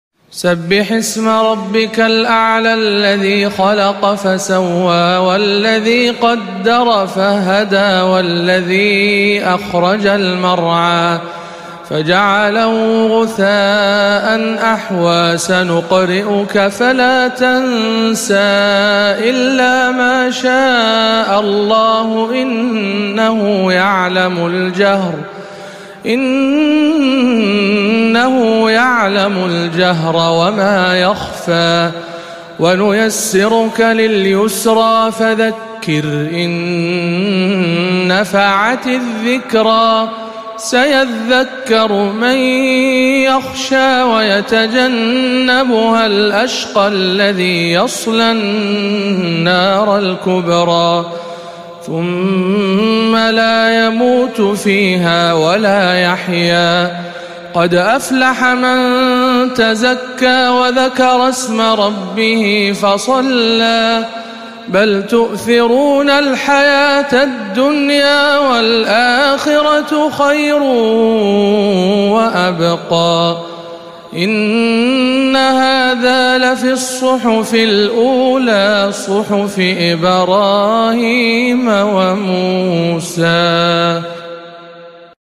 28. سورة الأعلى - تلاوات رمضان 1437 هـ